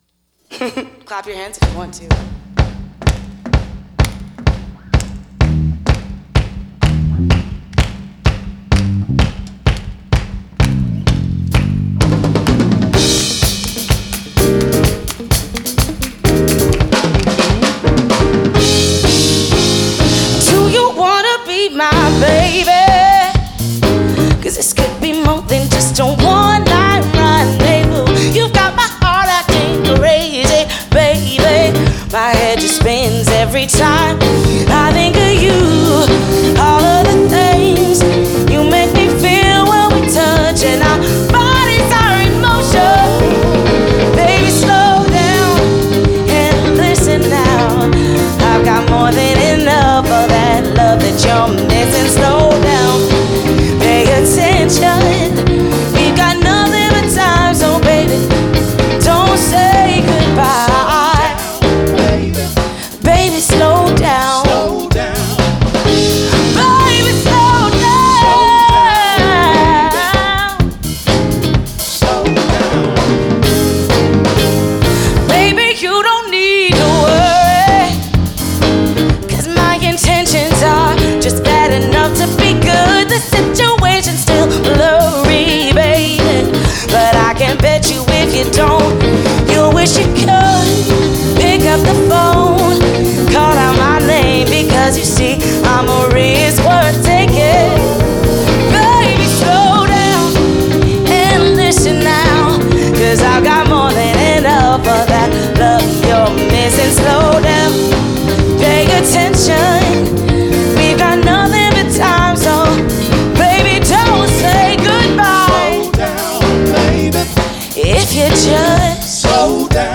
バンドレコーディング向けマルチトラックのミックスサービスとなります。
マルチトラックサンプルミックス